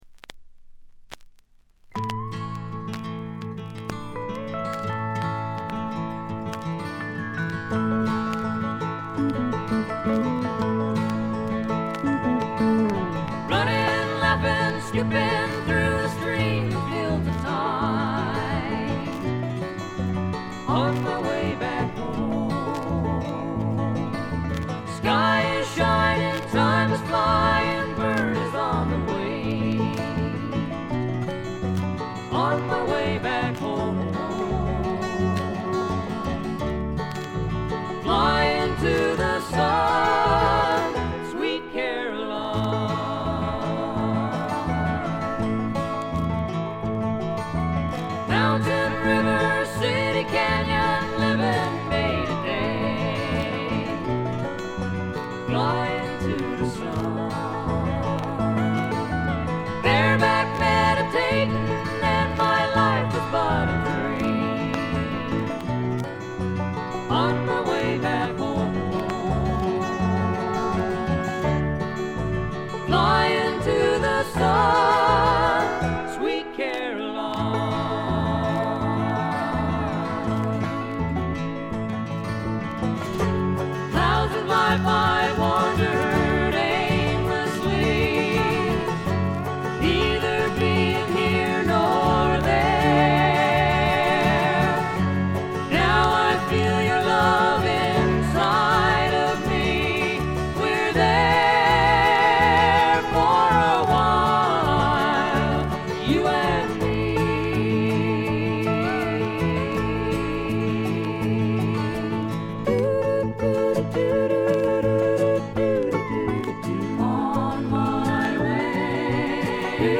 バックグラウンドノイズ、チリプチは大きめで半分ほどでほぼ常時出ます。
アラスカ産の自主制作ヒッピー・フォーク。
音の方は男3女2の構成のオーソドックスなフォークです。
リードヴォーカルはほとんどが美しいフィメールなので、普通にフィメールフォーク作品として聴いていただけます。
いかにもアラスカらしい清澄な空気感と美しい女声ヴォーカルの妙をお楽しみください。
試聴曲は現品からの取り込み音源です。